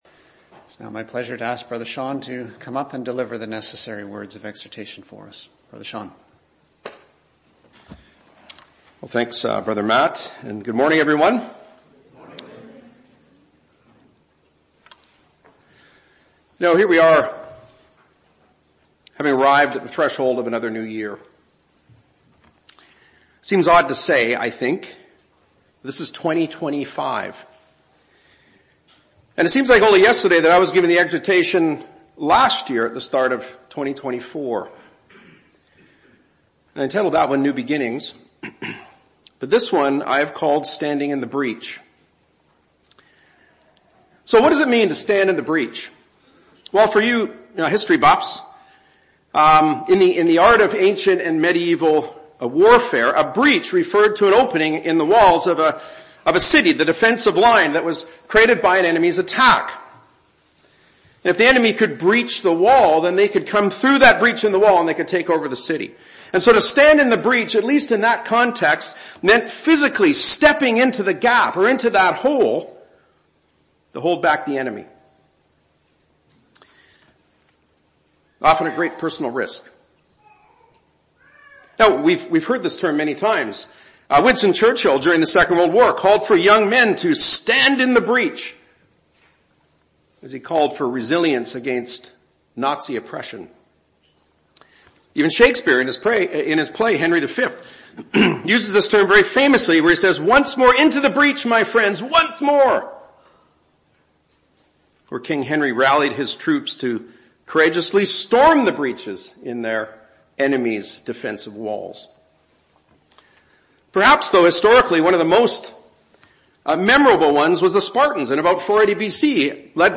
Exhortation 01-05-25